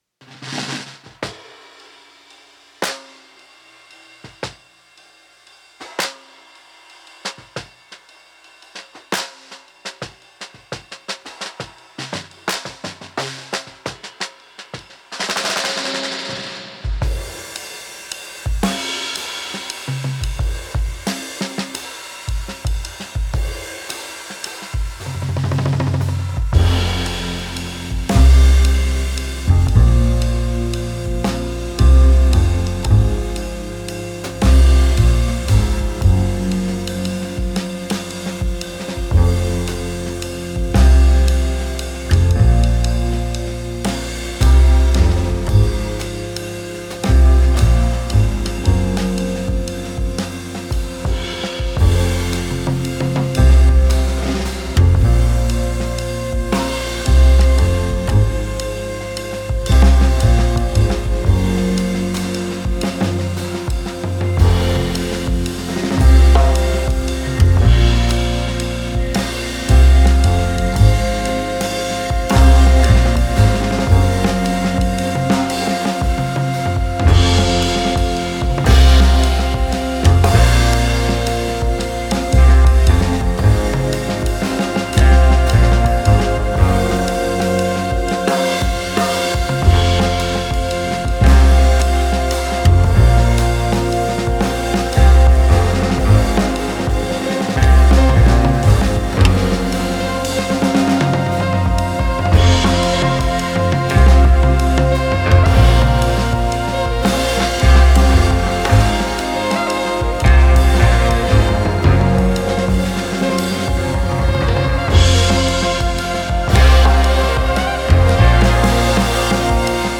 contrebasse, basse, flûte, synthétiseurs, boite à rythme
alto saxophone, effects
batterie, percussions, machines
guitare, effets